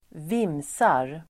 Ladda ner uttalet
vimsa verb (vardagligt), fiddle about [informal]Grammatikkommentar: A &Uttal: [²v'im:sar] Böjningar: vimsade, vimsat, vimsa, vimsarDefinition: uppträda vimsigt